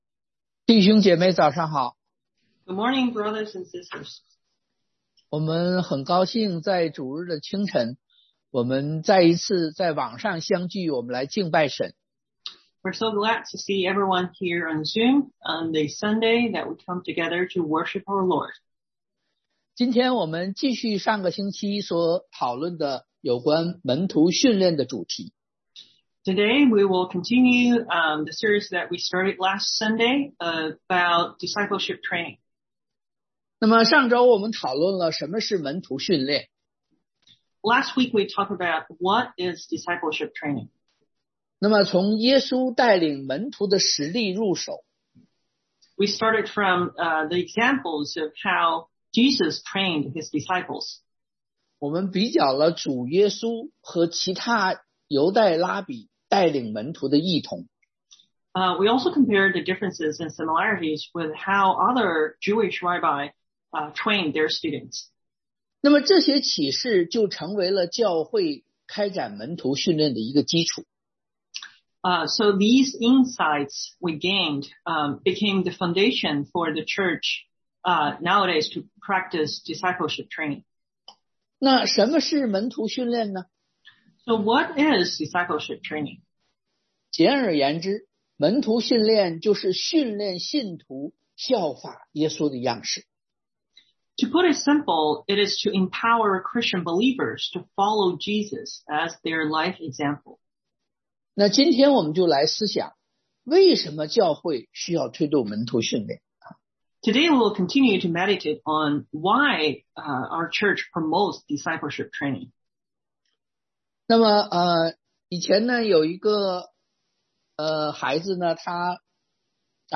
Mat 28:16-20 Service Type: Sunday AM Why Do We Promote Discipleship Training?